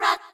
rahRahSisBoomBaBoomgirls2.ogg